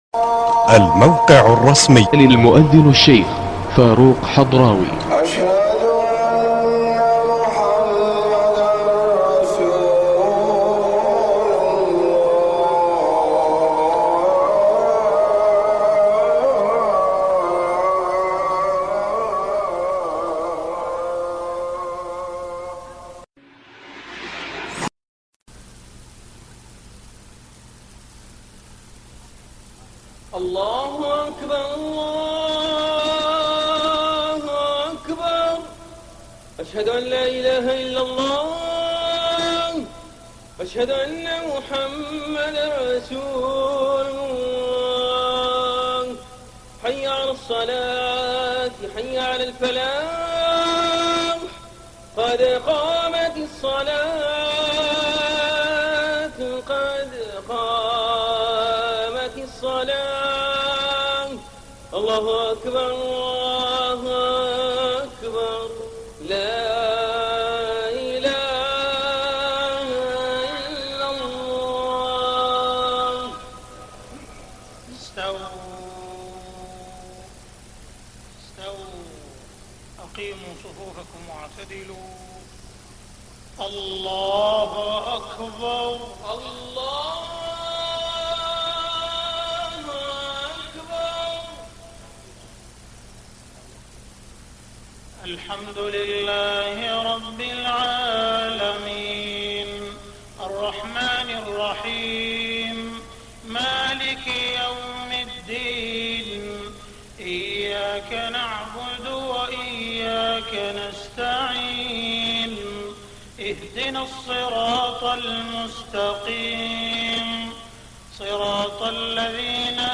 صلاة المغرب 8 رمضان 1423هـ سورة البينة > 1423 🕋 > الفروض - تلاوات الحرمين